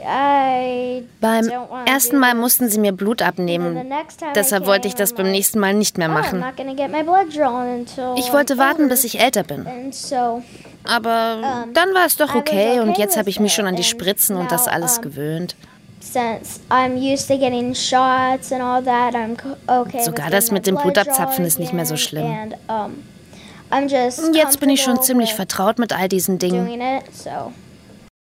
markant, sehr variabel, hell, fein, zart
Children's Voice (Kinderstimme)